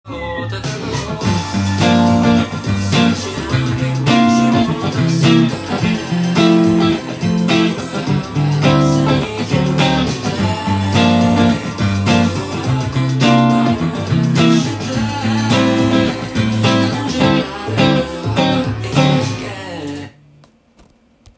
今日は、エレキギターで弾けるように
音源にあわせて譜面通り弾いてみました
tab譜では基本４和音のコードが使用され　ミュートも出てきます。
×印のミュートを作り出せるとノリが出てカッコよくなります。